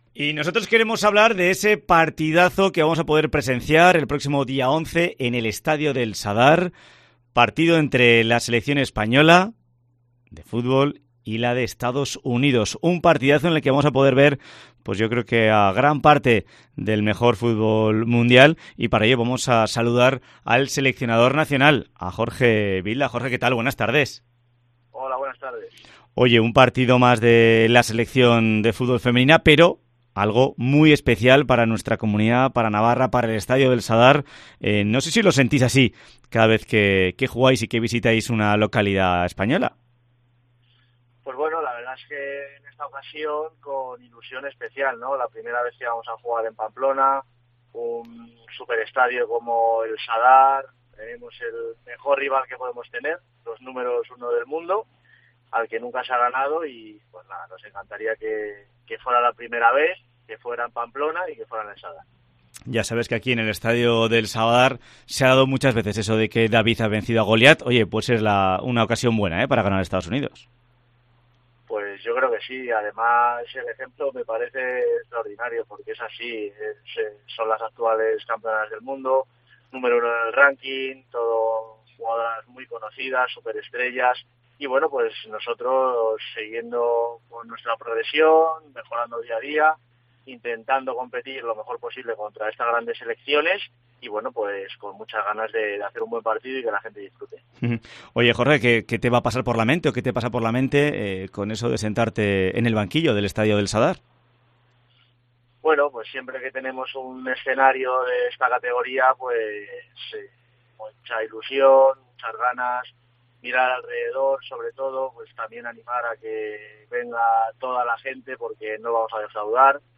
Entrevista en COPE Navarra con el seleccionador nacional Jorge Vilda